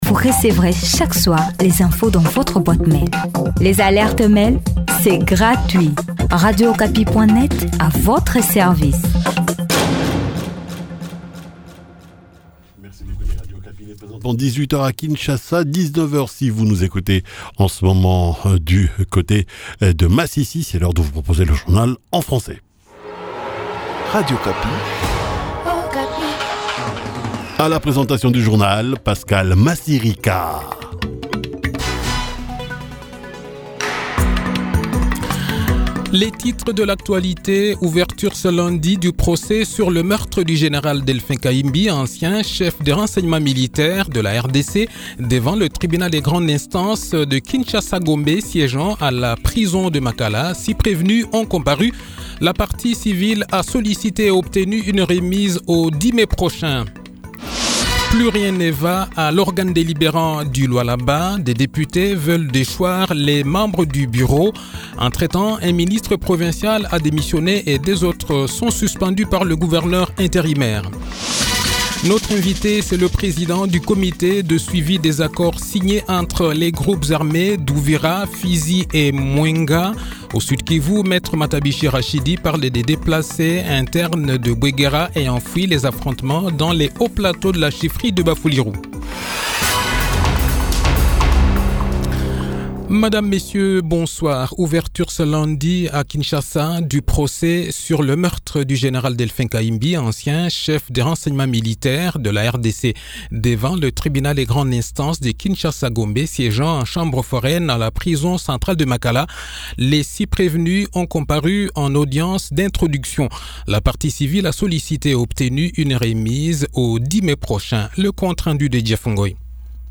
Le journal-Français-Soir